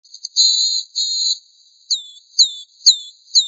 En cliquant ici vous entendrez le chant de la Mésange boréale.